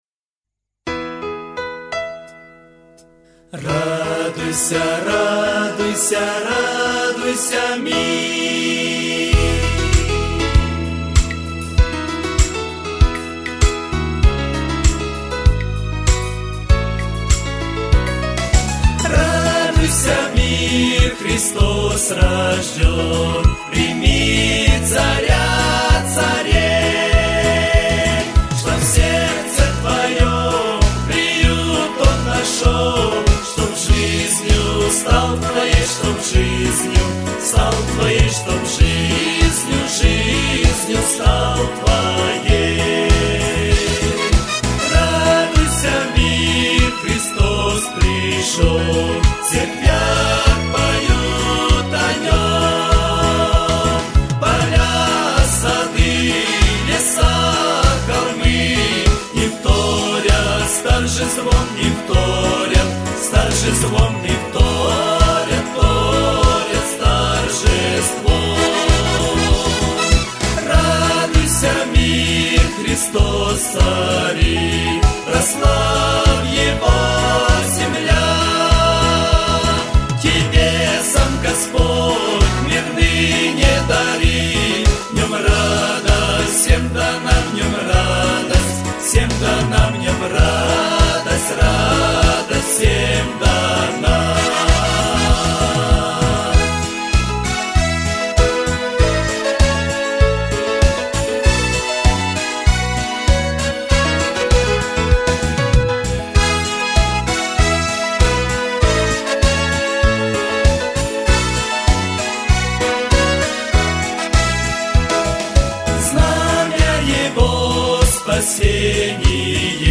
песня
708 просмотров 1182 прослушивания 117 скачиваний BPM: 75